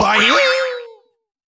boing.ogg